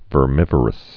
(vər-mĭvər-əs)